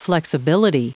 Prononciation du mot flexibility en anglais (fichier audio)
Prononciation du mot : flexibility